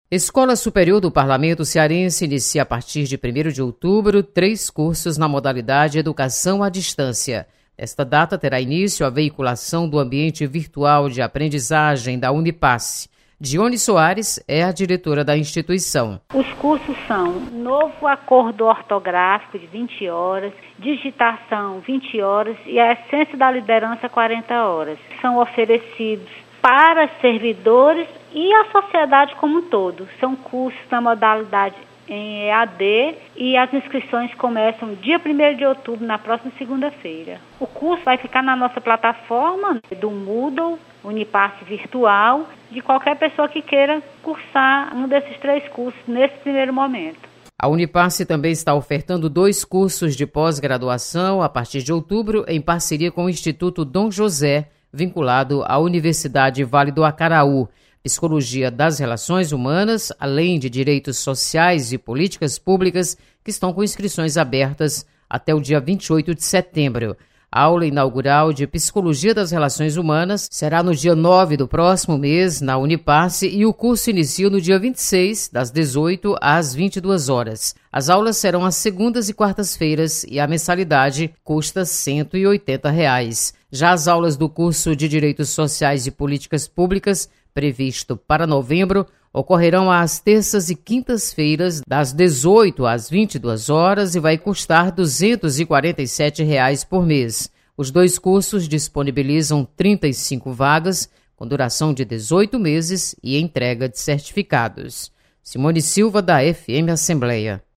Você está aqui: Início Comunicação Rádio FM Assembleia Notícias Unipace